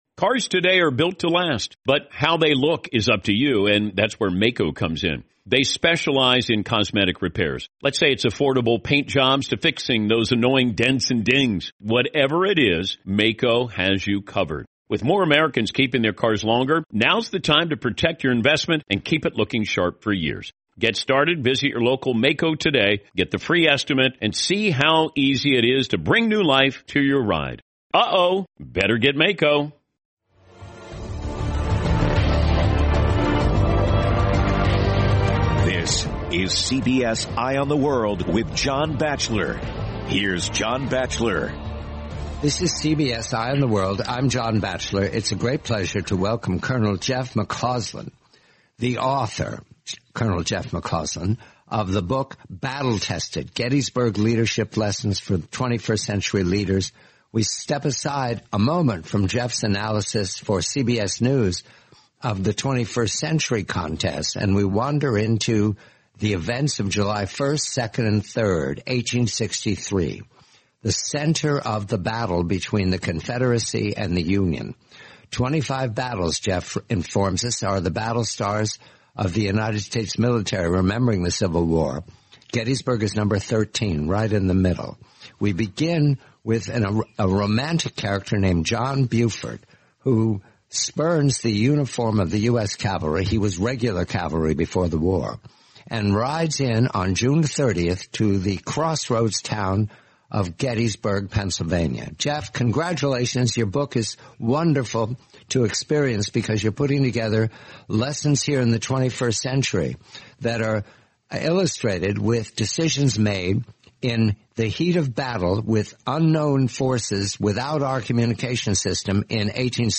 The complete forty-minute interview.